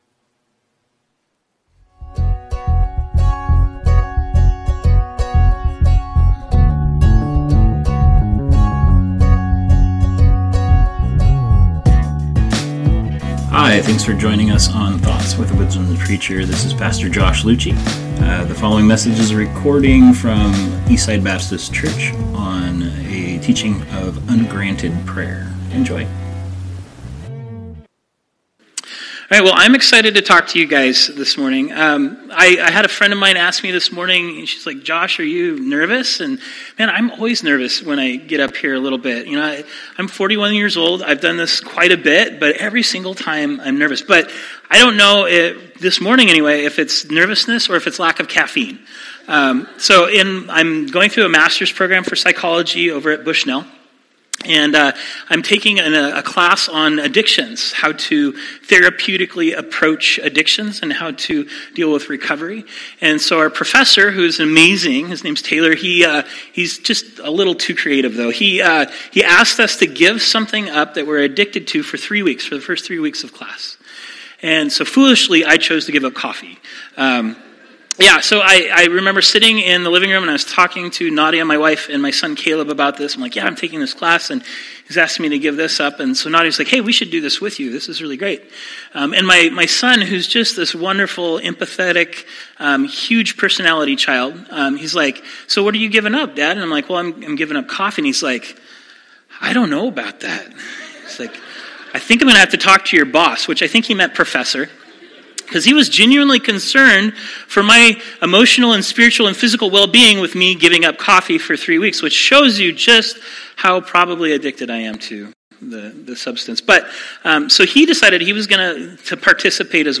A touching sermon on Ungranted Prayer